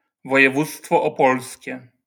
オポレ県Opole Province[4] (ポーランド語: województwo opolskie [vɔjɛˈvut͡stfɔ ɔˈpɔlskʲɛ] (